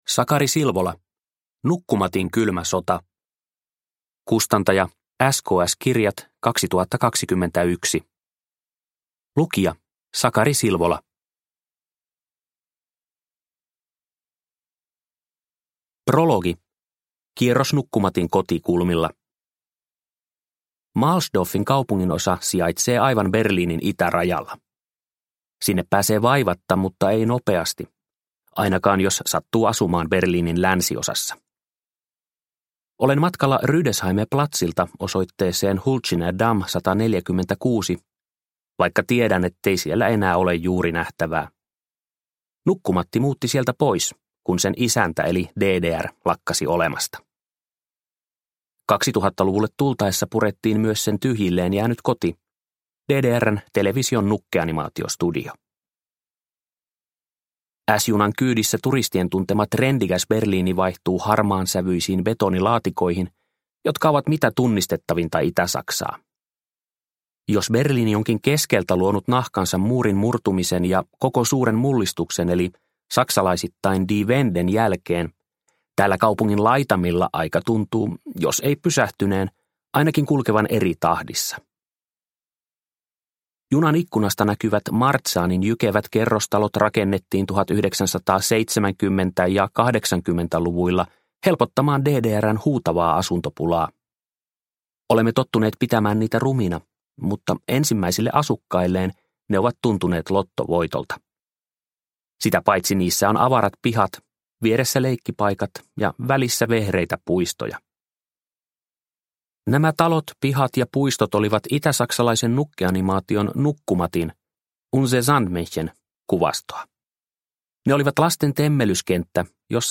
Nukkumatin kylmä sota – Ljudbok – Laddas ner